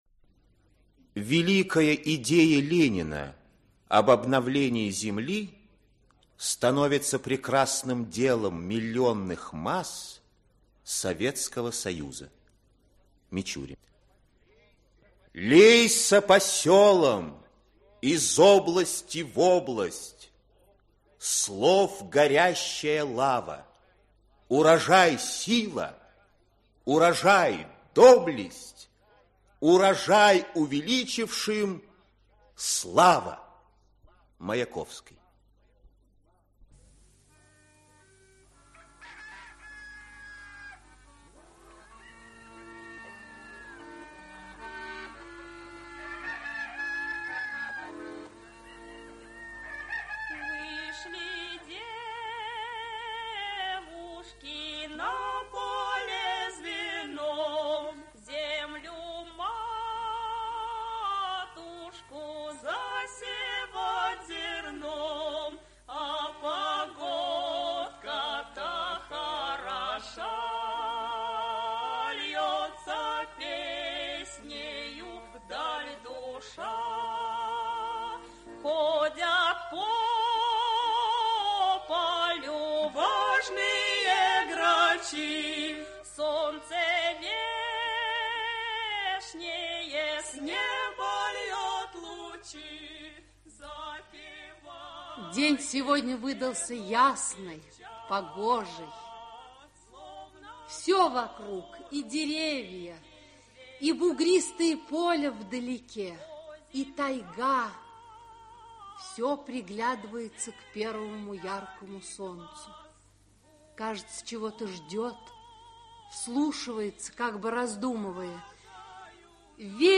Aудиокнига Народный академик Автор Валентин Овечкин Читает аудиокнигу Актерский коллектив.